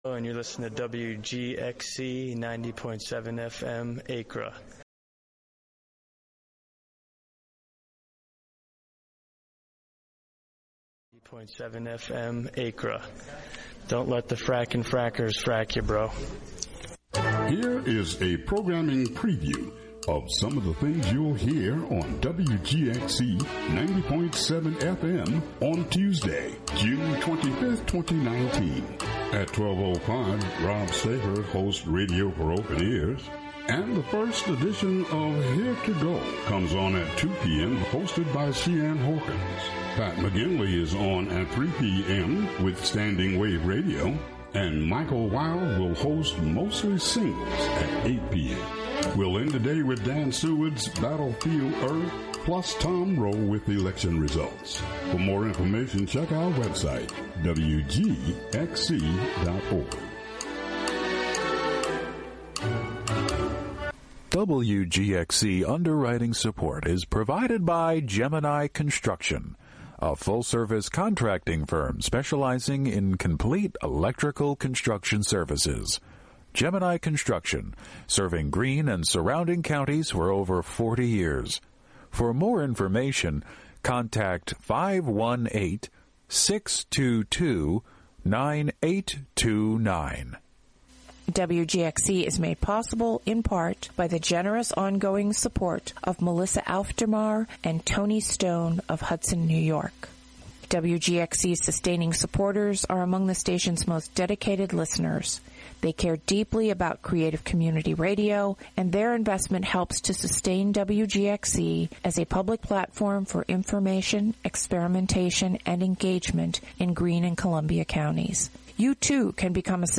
Broadcast live HiLo in Catskill.